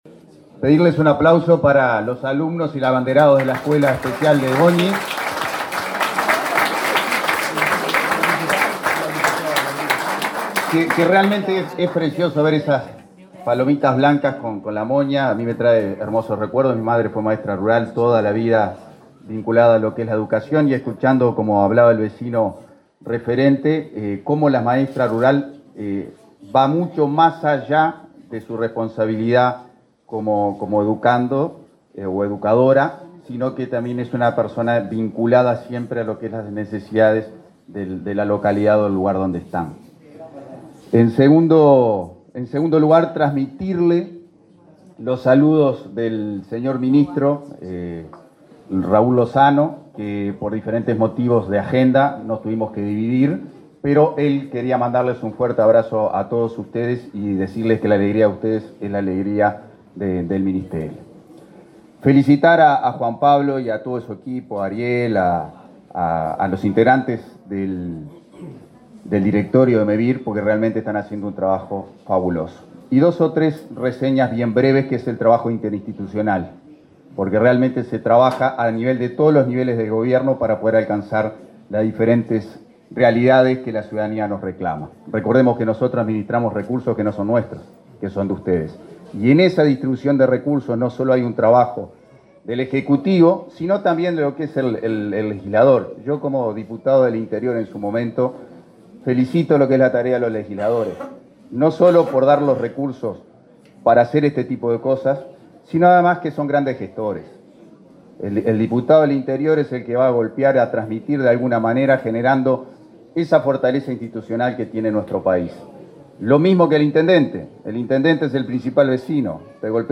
Palabra de autoridades en acto de Mevir en Florida